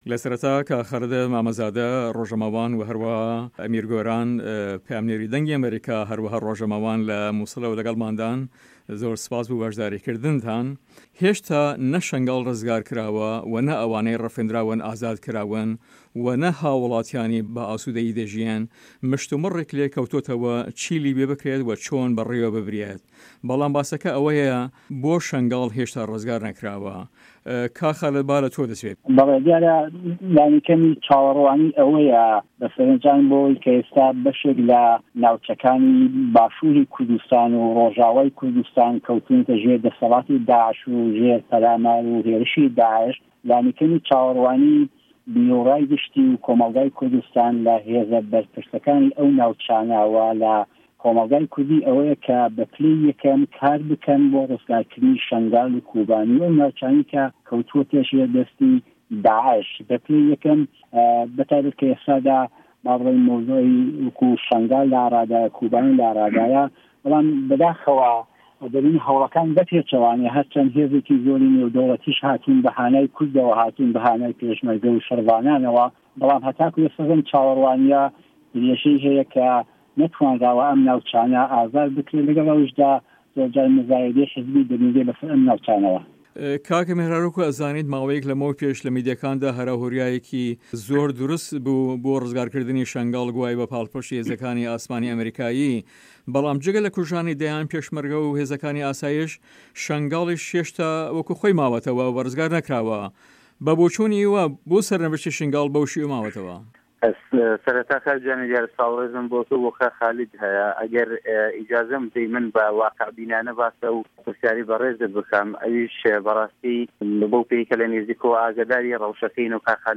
مێزگرد: شه‌نگاڵ له‌ نێوان رزگار ‌کردندن و پرۆسه‌ی به‌ کانتۆن کردنیدا